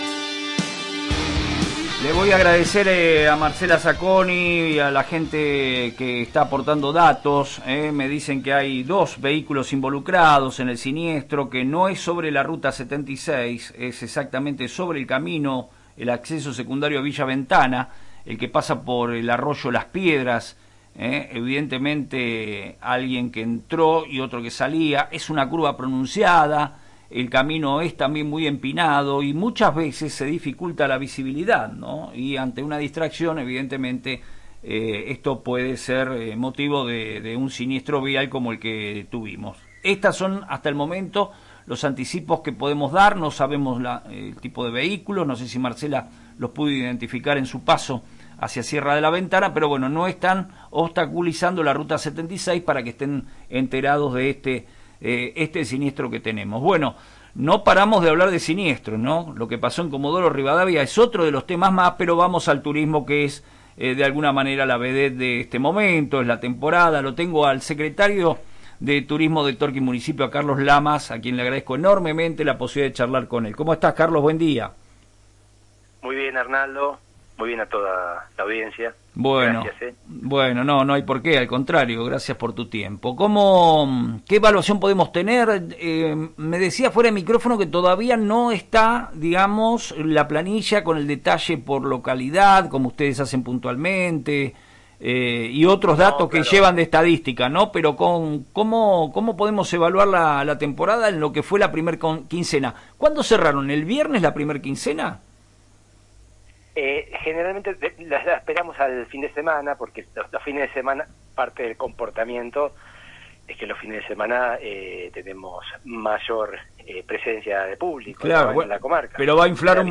El titular de la cartera turística, Carlos Lamas, aseguró en FM Reflejos que la clave de este verano ha sido la flexibilidad de los prestadores para acomodar valores a la realidad bonaerense.